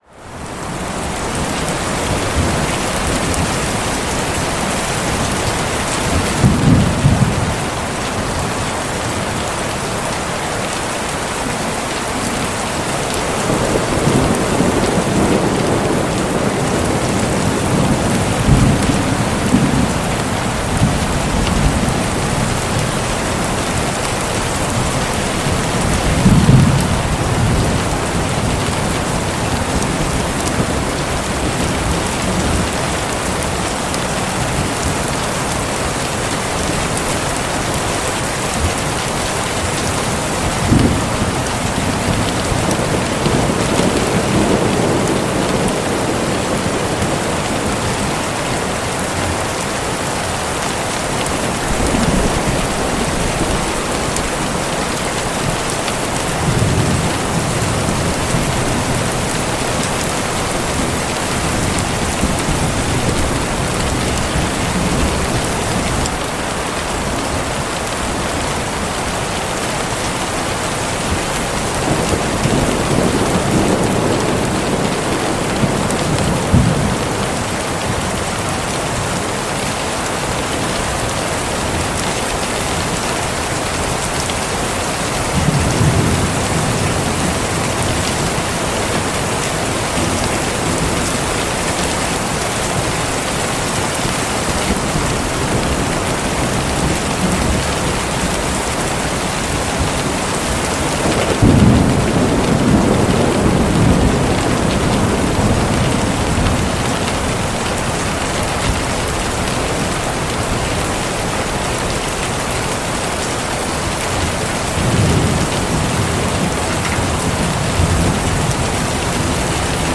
Rainy Day Camper Van Window 1 Hour Sleep Study Relax